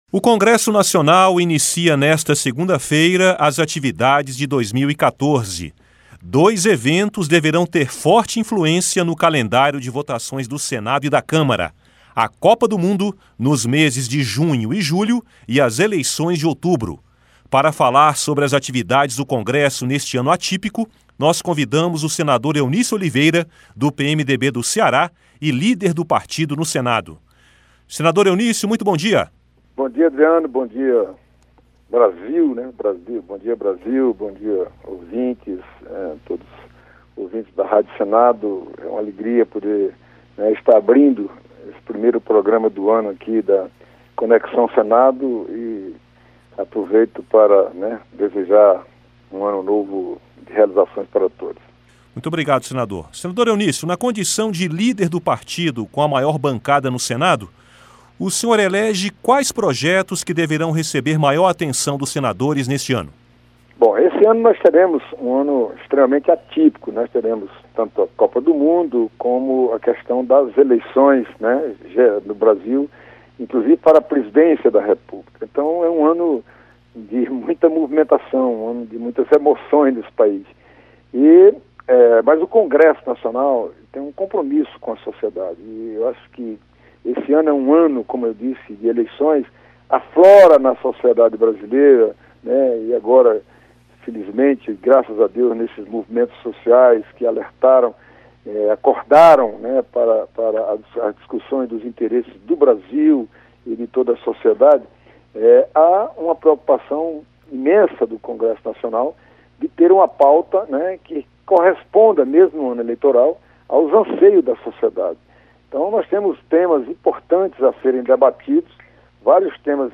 Entrevista com o senador Eunício Oliveira (PMDB-CE).